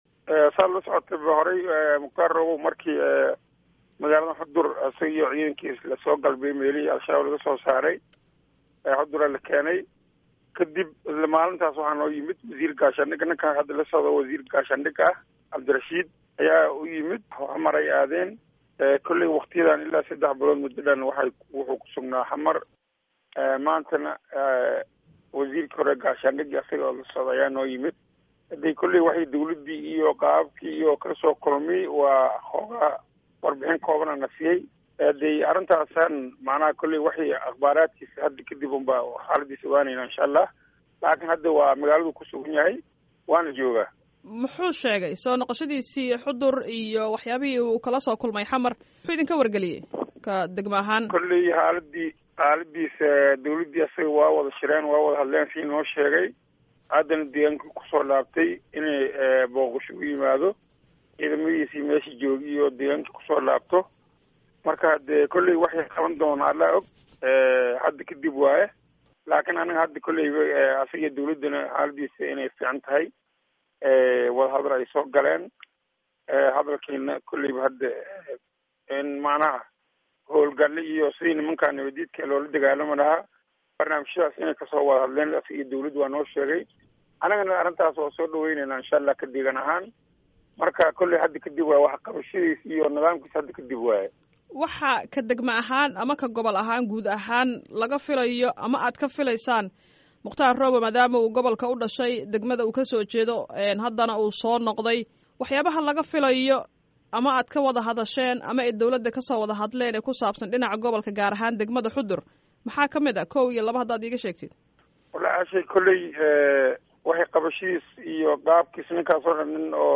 Gudoomiyaha degamada Xudur Maxamed Macalin.